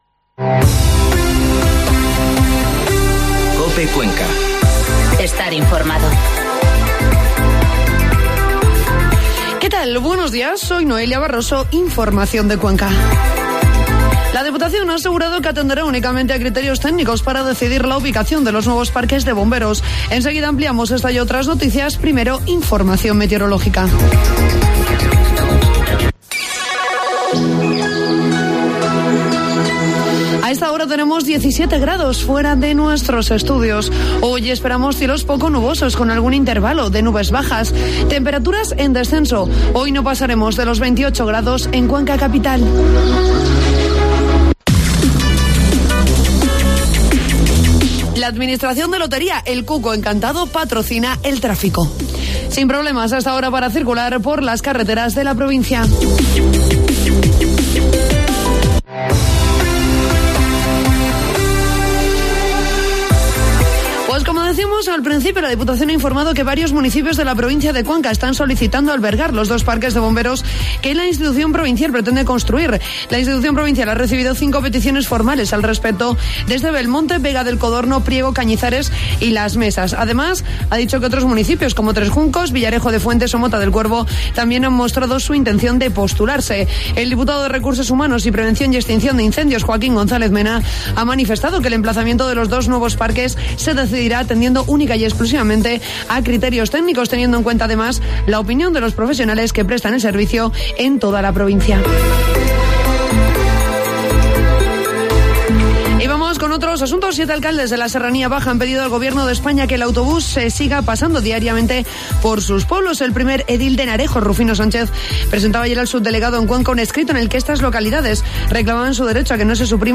Informativo matinal COPE Cuenca 5 de septiembre